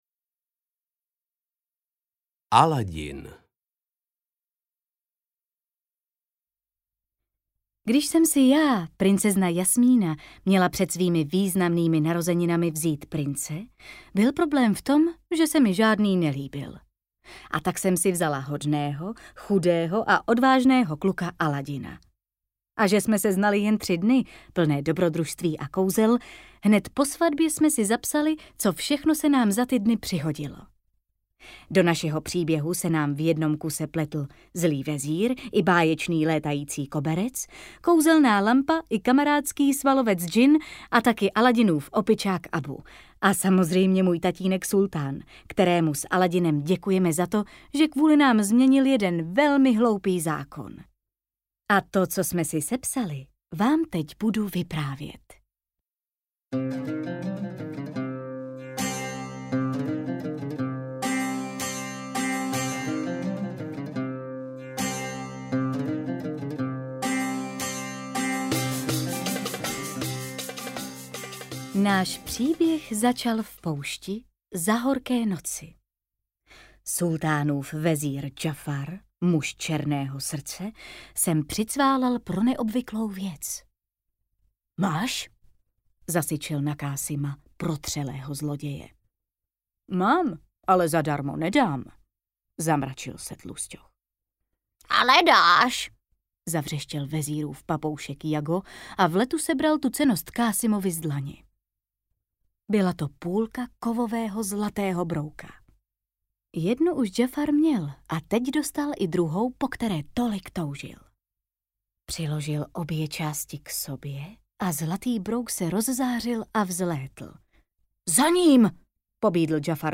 AudioKniha ke stažení, 3 x mp3, délka 2 hod. 19 min., velikost 126,8 MB, česky